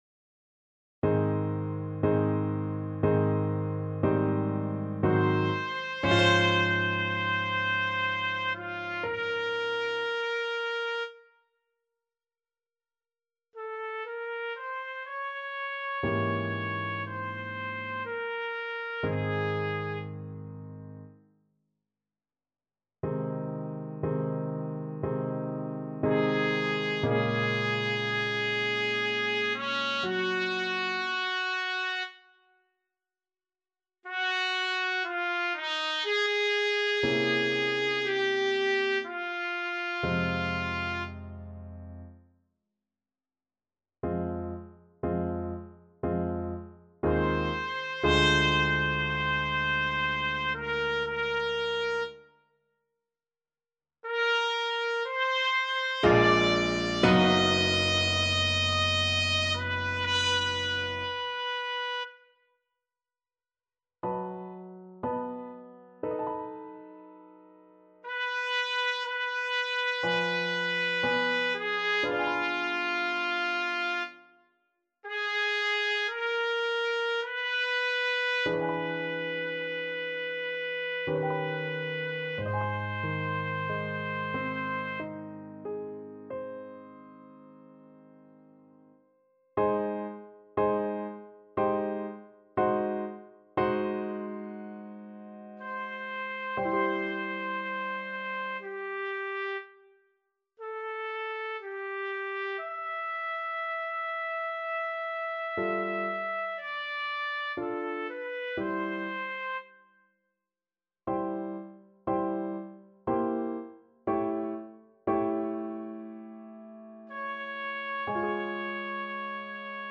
Trumpet
Ab major (Sounding Pitch) Bb major (Trumpet in Bb) (View more Ab major Music for Trumpet )
3/4 (View more 3/4 Music)
~ = 60 Langsam, leidenschaftlich
Classical (View more Classical Trumpet Music)